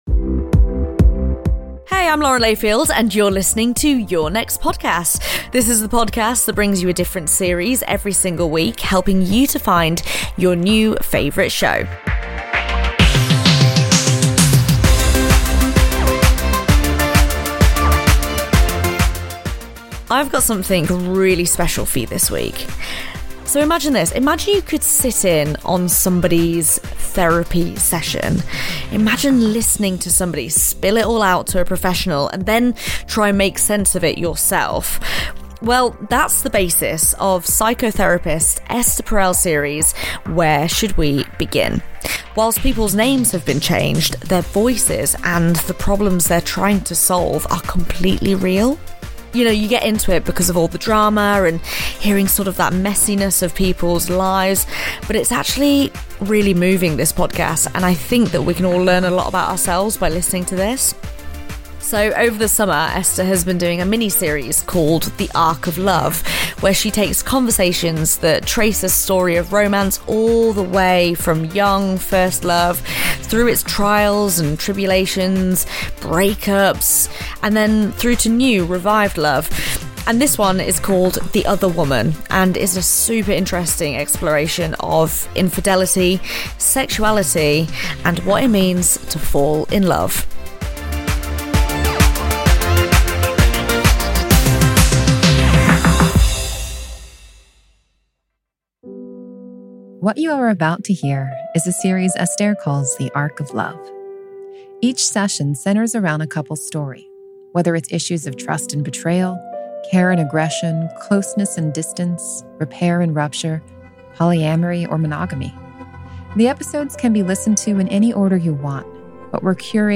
Lauren Layfield introduces Where Should We Begin? on the series recommendation show, Your Next Podcast.